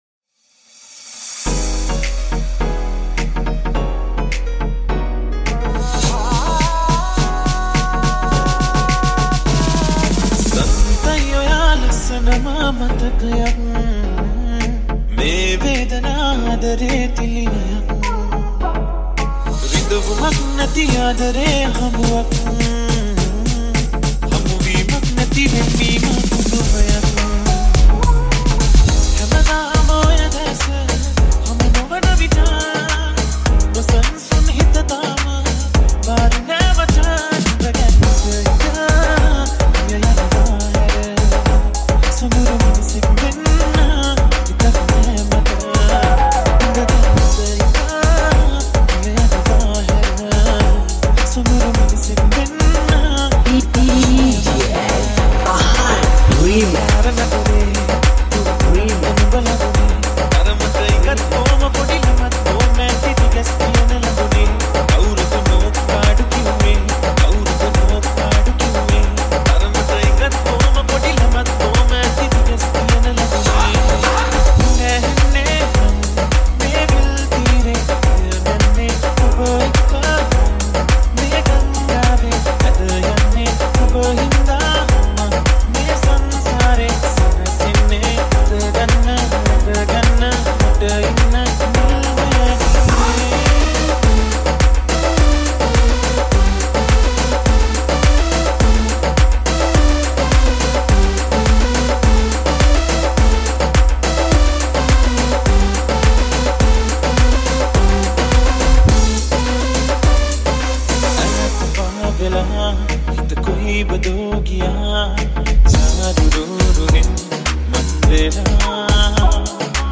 Official EDM N Punjab Dance Dj Remix